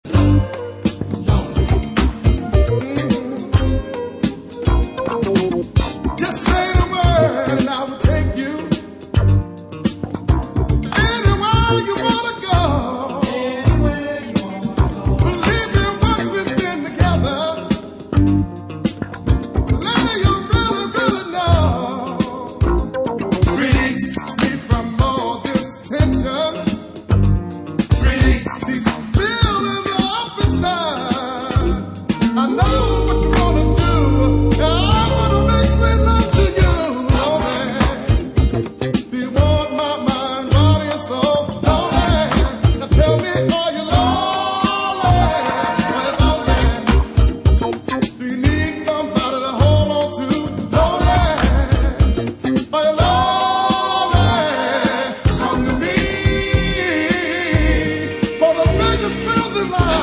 a super cool slab of breezy uplifti... more...
Disco